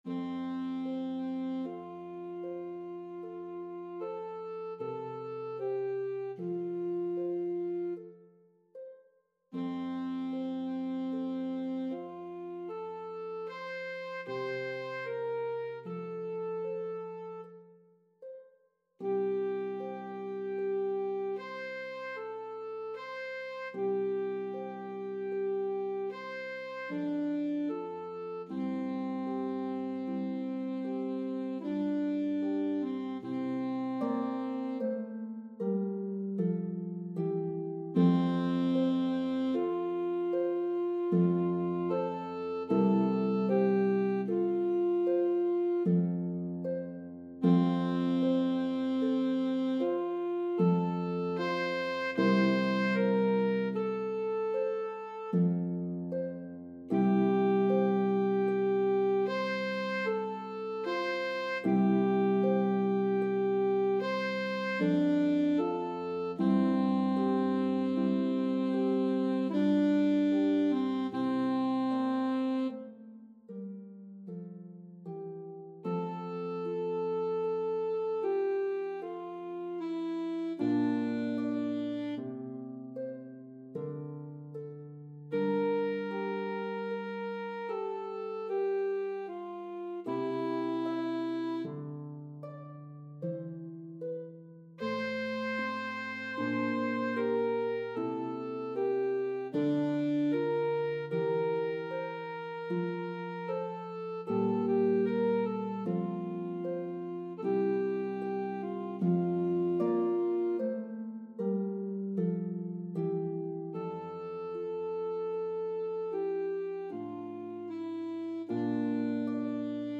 The orchestra parts play beautifully on a pedal harp.